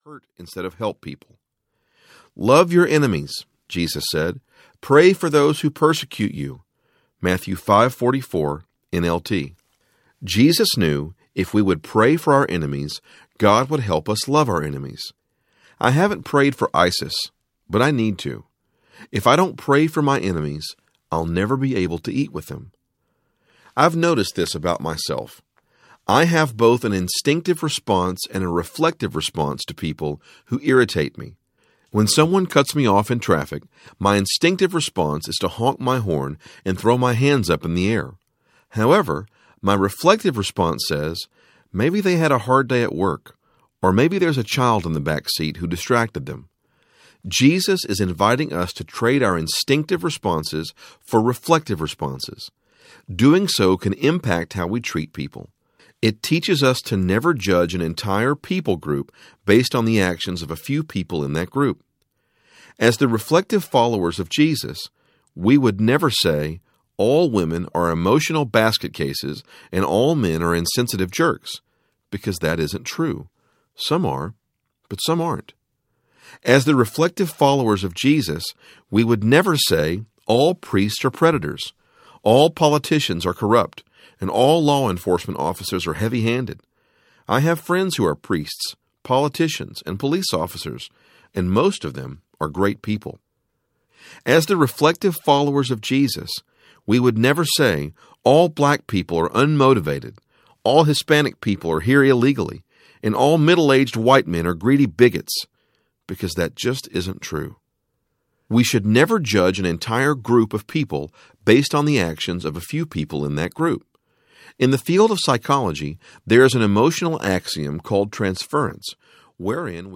Me Too Audiobook
4.6 Hrs. – Unabridged